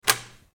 Mechanical Latch Click Sound Effect
Mechanical-latch-click-sound-effect.mp3